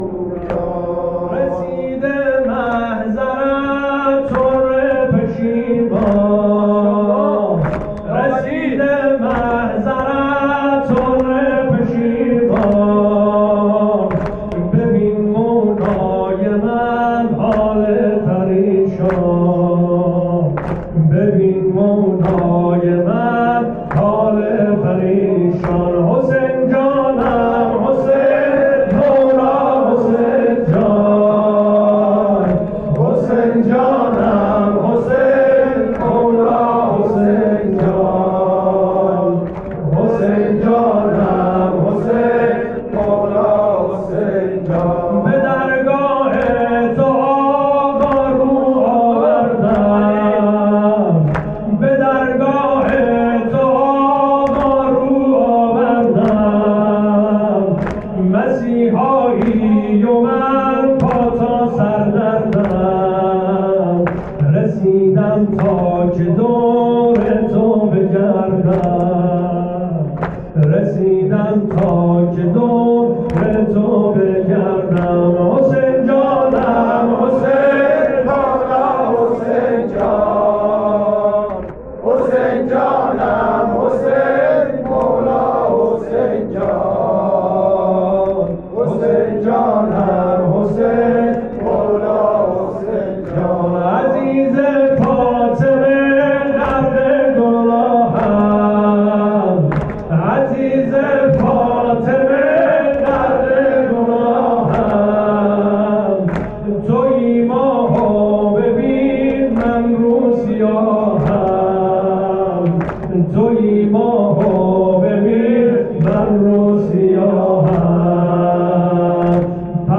شب پنجم محرم
مداحی